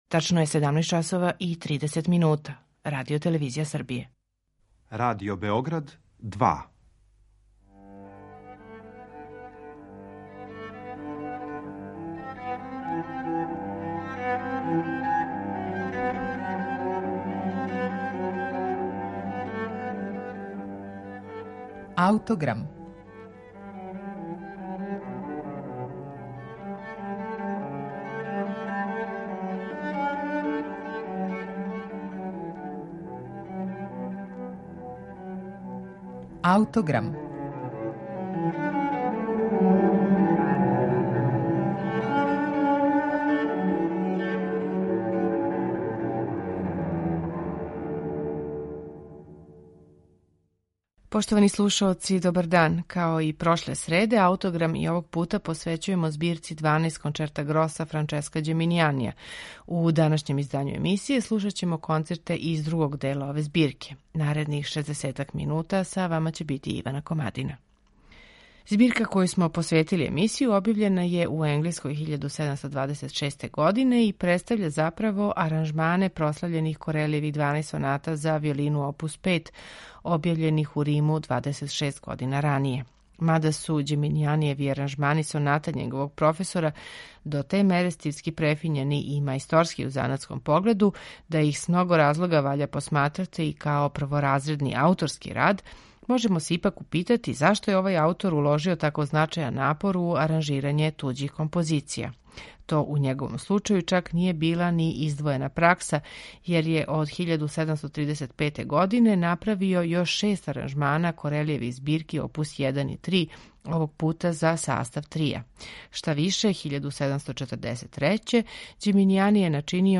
Слушаћете их у интерпретацији ансамбла "I musici".